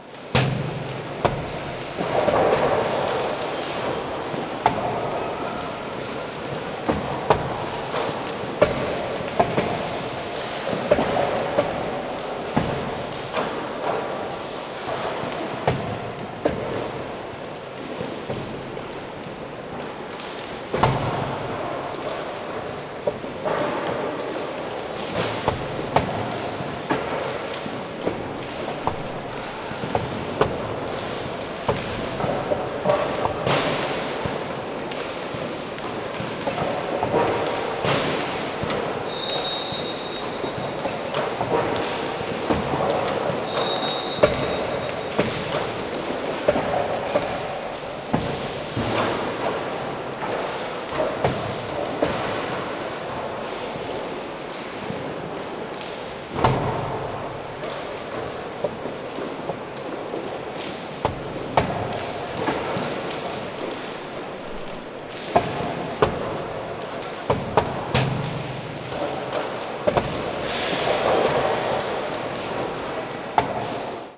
Antes de un partido de hoquei, un deporte muy popular en Catalunya, es un momento de ruido caótico ya que todos los jugadores calientan cada uno con una pelota.
[ENG] Before a hockey game, a very popular sport in Catalonia, it is time of chaotic noise .
hoquei.wav